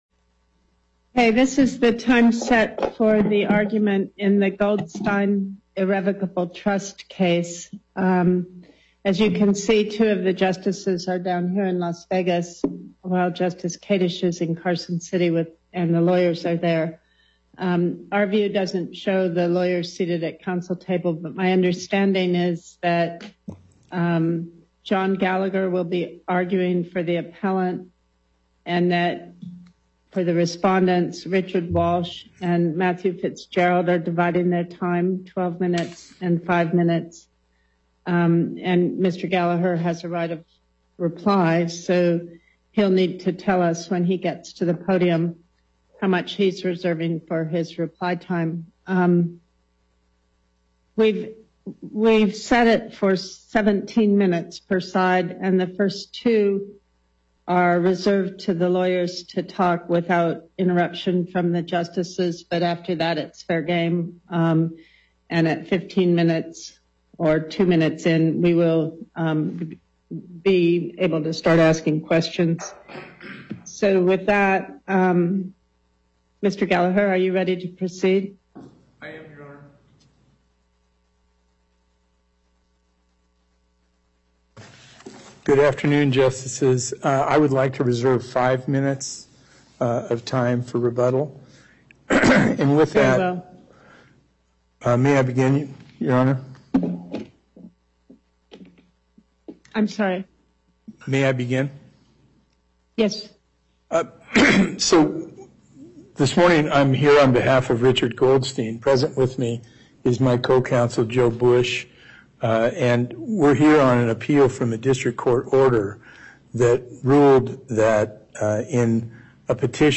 Before Panel B25, Justice Pickering presiding